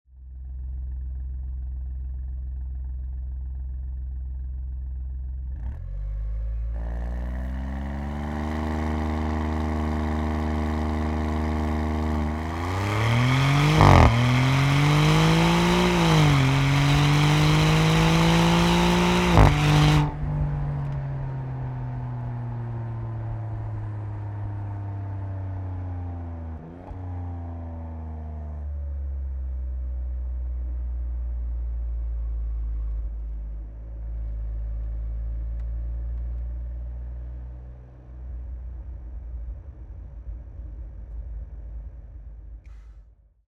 Une signature sonore d'exception
Le rugissement d’une légende italienne où puissance et luxe se rencontrent
moteur.mp3